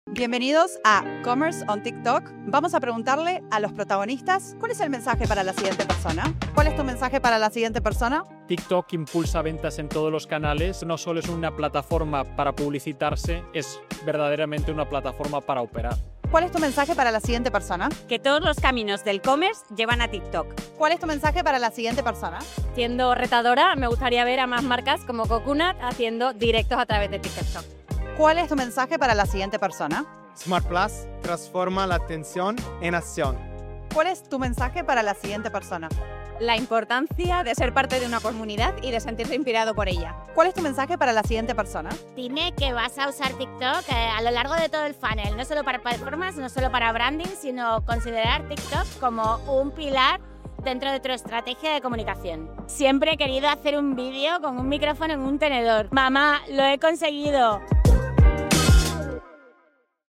Estos son los mensajes que surgieron en TikTok Commerce Week 2025: micrófono en mano, ideas que inspiran y un mensaje que sigue rodando.